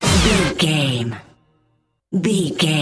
Ionian/Major
synthesiser
drum machine
Eurodance